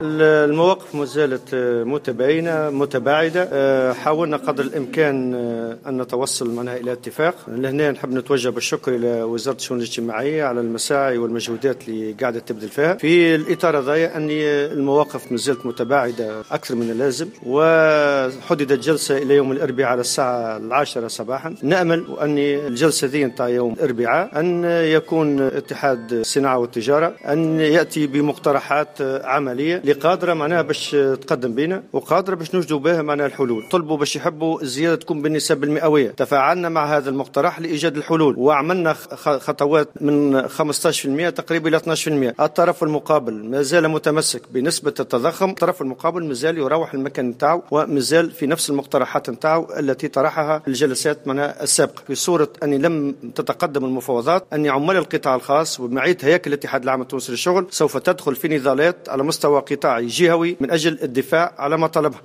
وكشف نور الدين الطبوبي الأمين العام المساعد بالاتحاد العام التونسي للشغل في تصريحات صحفية عن طلب منظمة الأعراف بتحديد الزيادة في الأجور بنحو 4.2 بالمائة قياسا بنسبة التضخم، معتبرا أن المواقف بين الطرفين مازالت متباعدة.